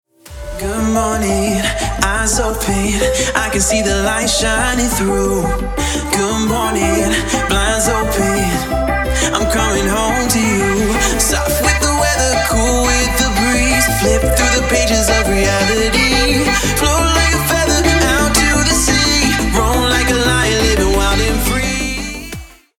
• Качество: 320, Stereo
мужской вокал
club
vocal